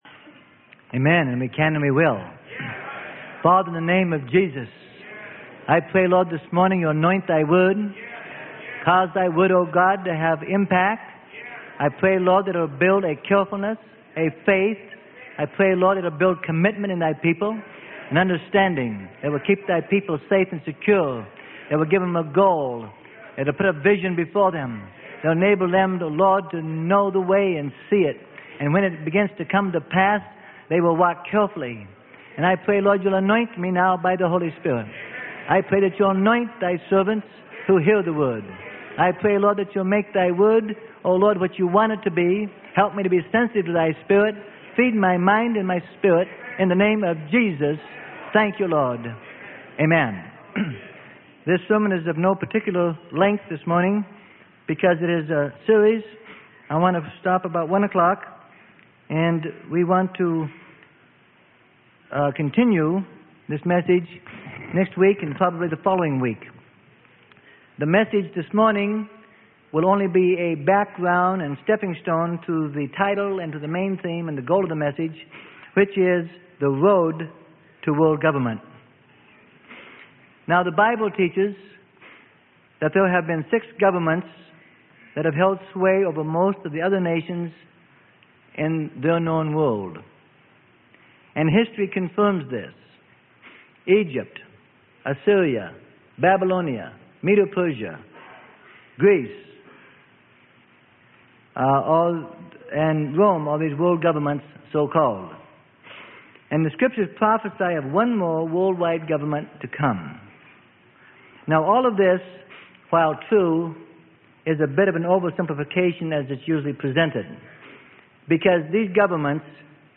Sermon: THE ROAD TO WORLD GOVERNMENT.